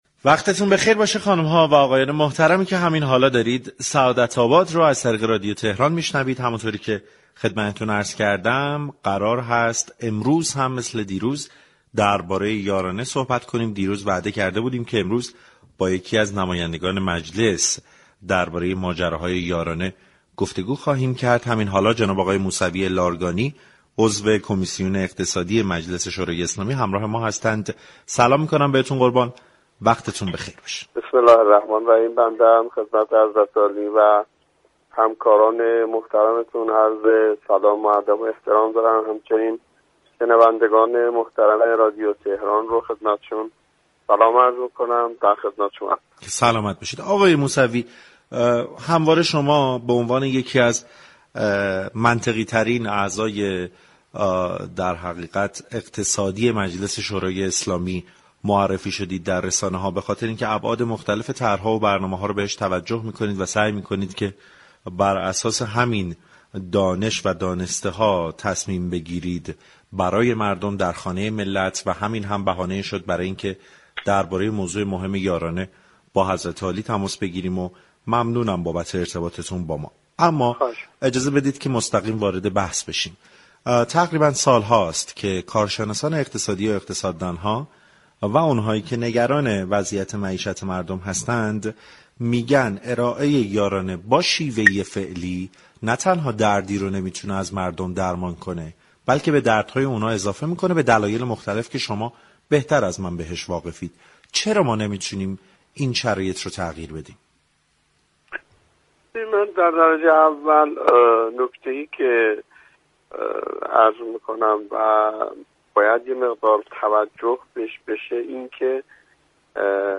سعادت آباد روز دوشنبه 10 آبانماه با حجت‌الاسلام سیدناصر موسوی لارگانی نماینده مجلس یازدهم در این خصوص گفتگو كرده است.